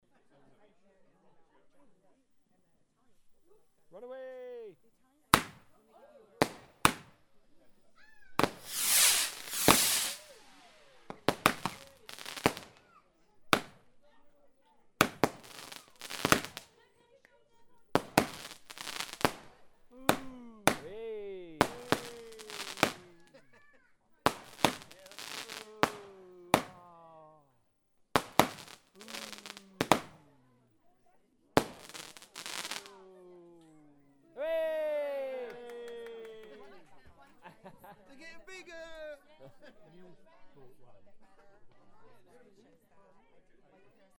fireworksshort.mp3